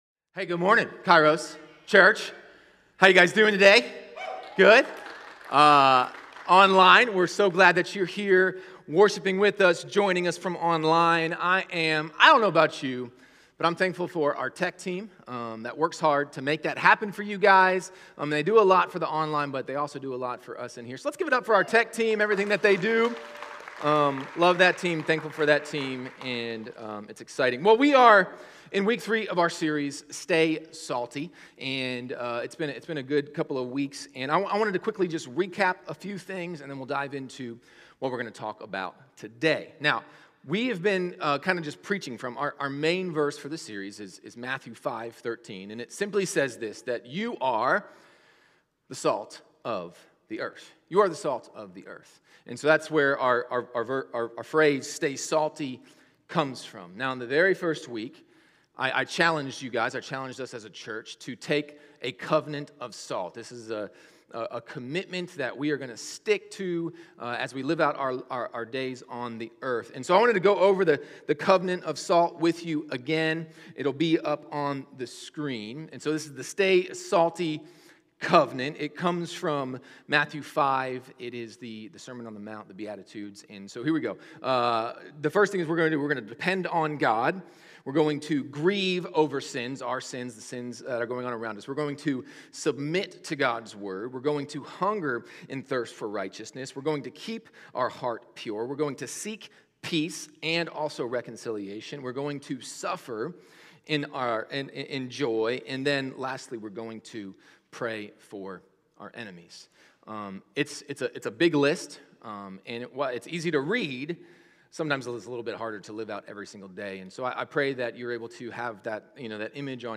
Sermons | Kairos Church